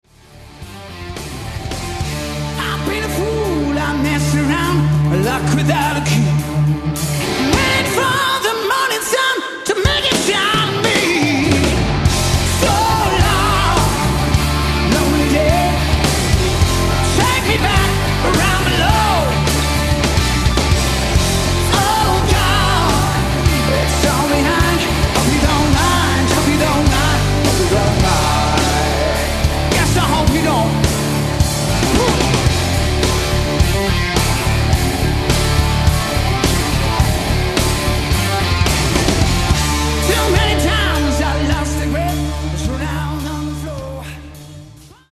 Recorded: Zurich, Switzerland, December 8 2005
Genre: Rock, hard rock